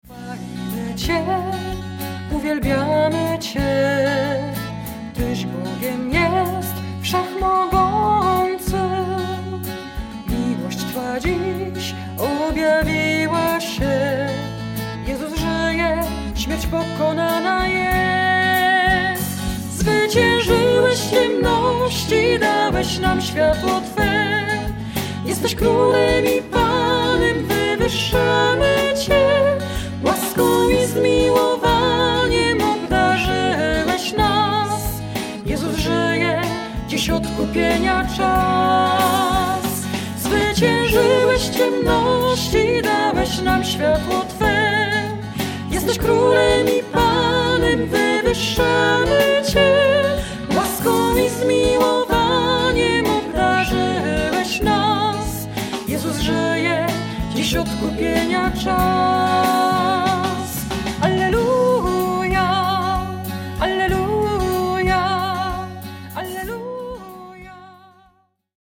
Usage : Louange